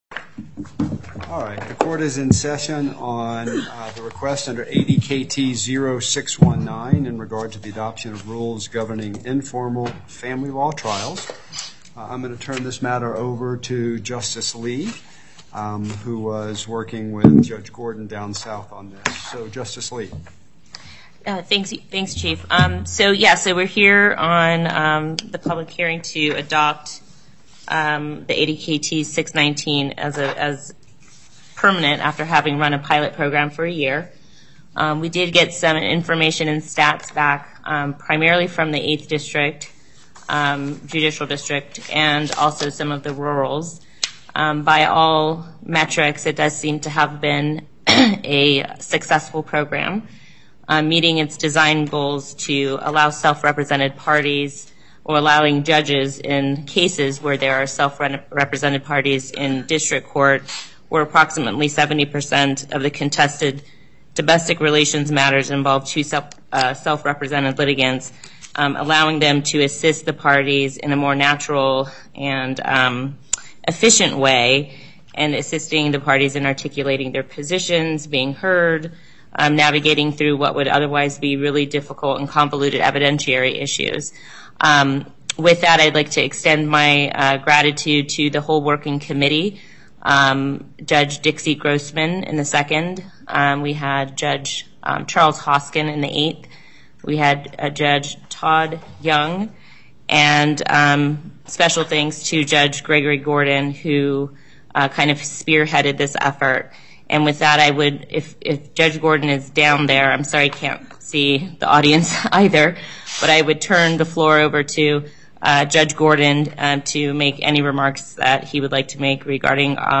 Before the En Banc Court, Chief Justice Herndon presiding Appearances